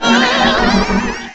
pokeemerald / sound / direct_sound_samples / cries / mismagius.aif